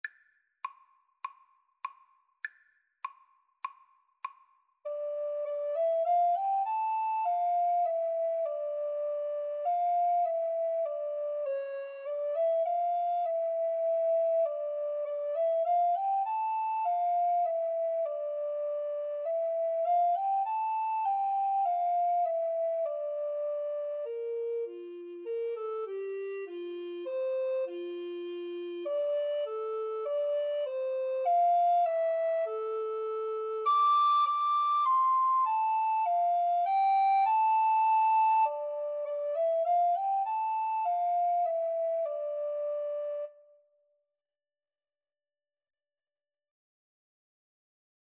Free Sheet music for Recorder Duet
D minor (Sounding Pitch) (View more D minor Music for Recorder Duet )
Moderato
Classical (View more Classical Recorder Duet Music)